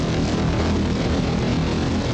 the cool HypnoToad sound in an endless loop